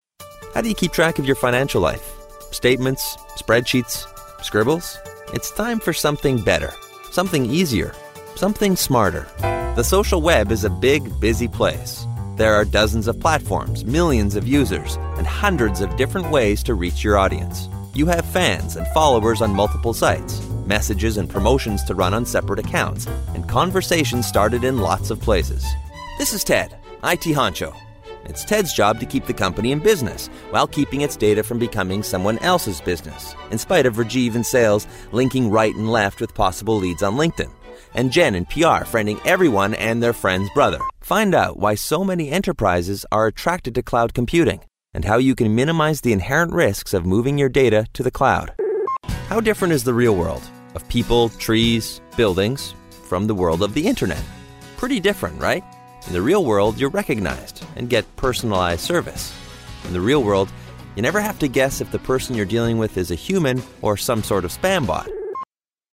englisch (us)
Sprechprobe: Industrie (Muttersprache):